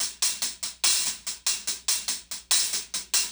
Closed Hats
HIHAT_IBANG.wav